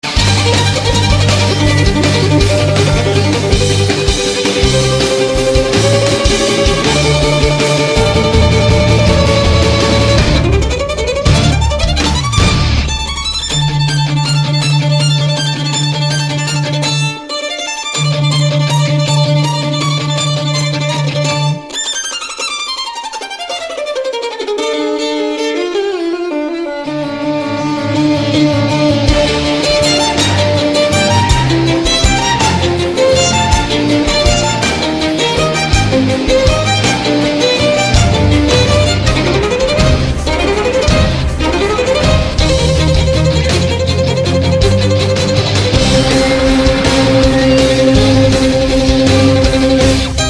小提琴 纯音乐